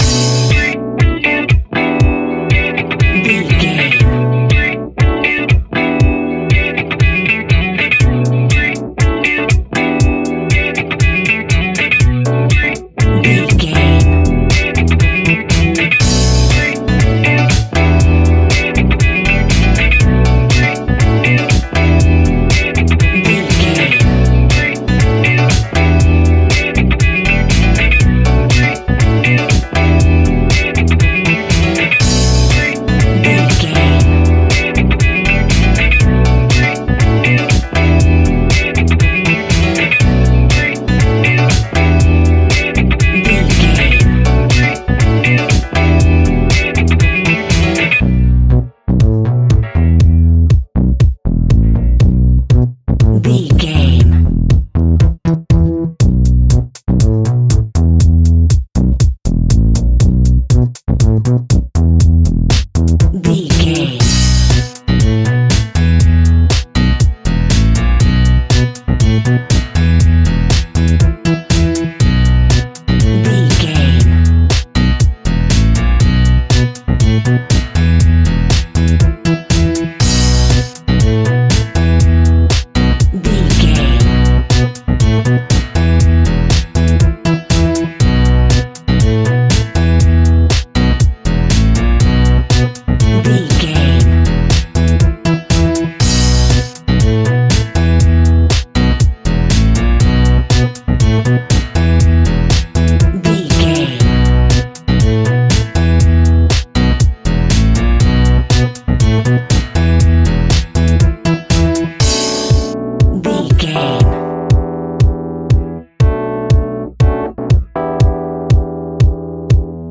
Aeolian/Minor
groovy
futuristic
industrial
hypnotic
uplifting
drum machine
synthesiser
electric guitar
drums
electric piano
funky house
disco house
electronic funk
bright
energetic
upbeat
synth leads
Synth Pads
synth bass